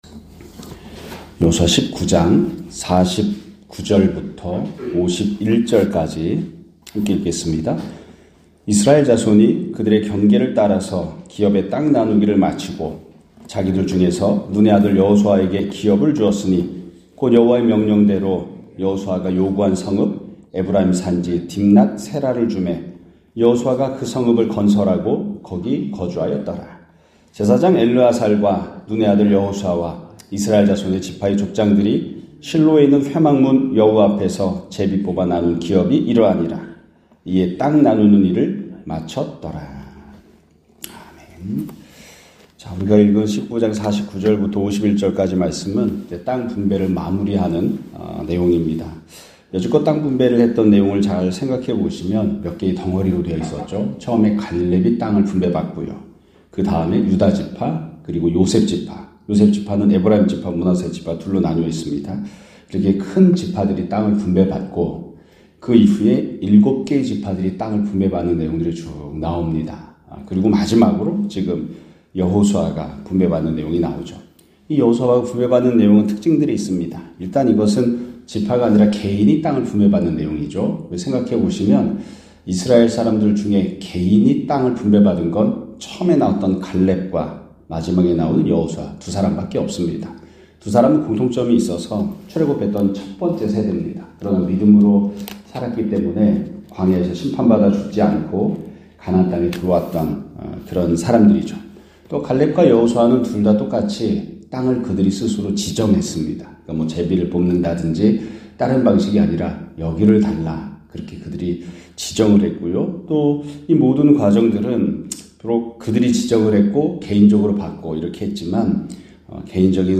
2025년 1월 13일(월요일) <아침예배> 설교입니다.